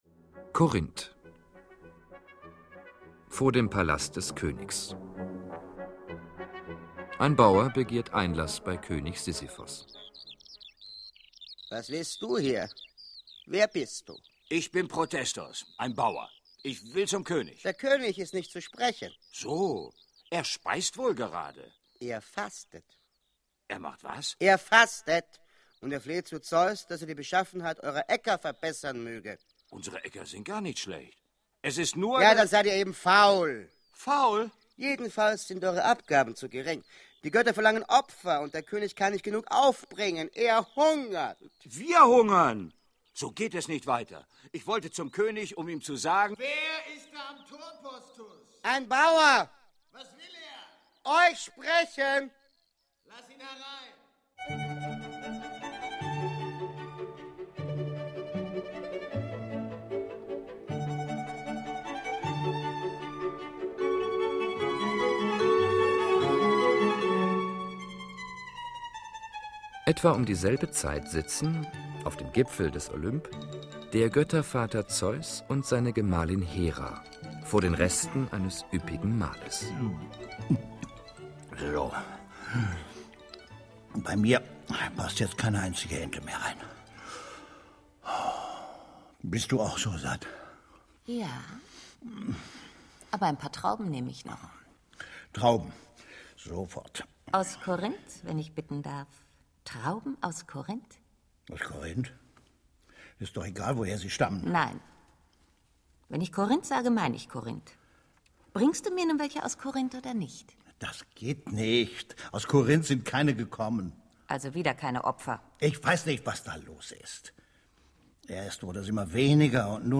Hörspiel (1 CD)
Eine humorvolle Hörspiel-Inszenierung des SWR, mit einem herausragenden Sprecher-Ensemble und einem überraschenden Ende.